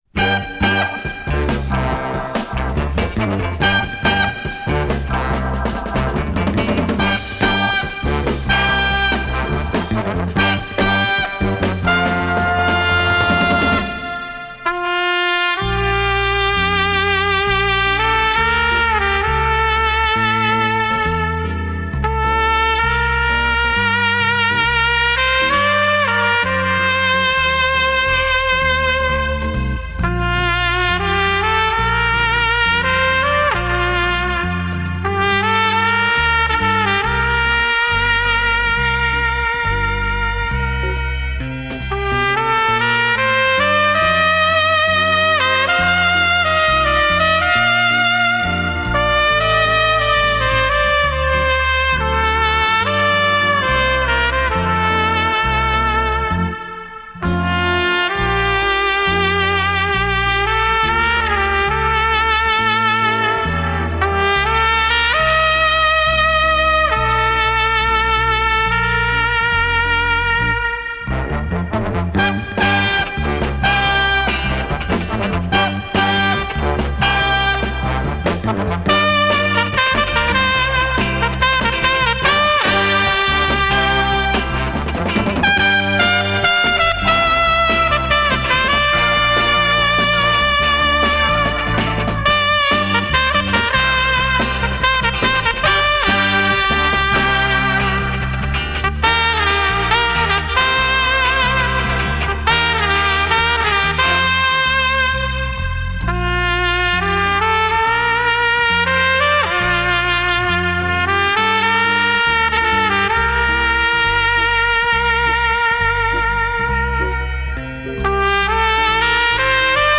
Французский трубач, руководитель оркестра.